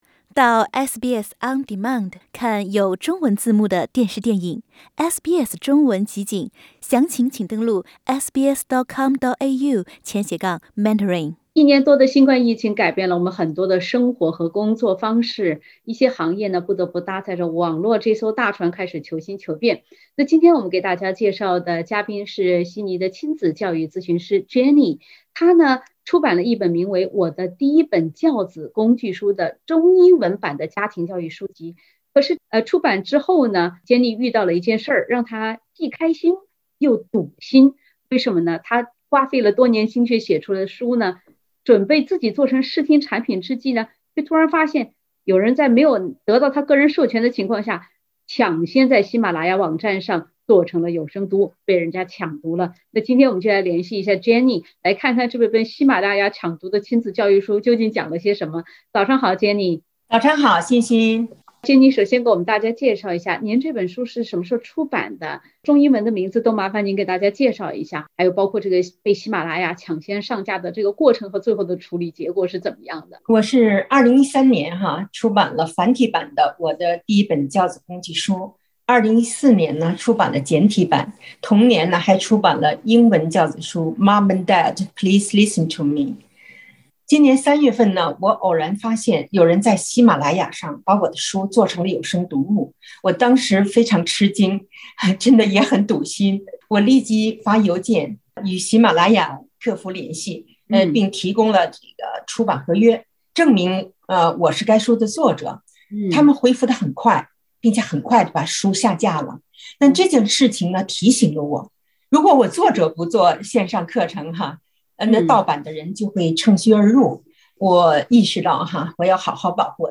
（点击封面图片，收听完整采访） 持续一年多的新冠疫情改变了我们很多的生活和工作模式。